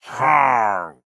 Minecraft Version Minecraft Version 25w18a Latest Release | Latest Snapshot 25w18a / assets / minecraft / sounds / mob / pillager / idle4.ogg Compare With Compare With Latest Release | Latest Snapshot